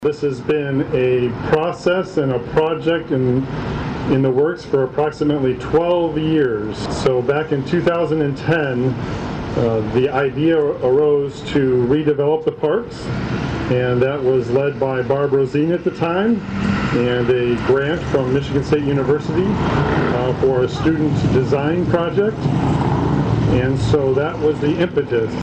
COLDWATER, MI (WTVB) – The renovated Four Corners Parks was formally re-dedicated on September 17 during a short program which included a ribbing cutting.
Coldwater City Manager Keith Baker said renovating the Four Corners Park had been discussed for over a decade.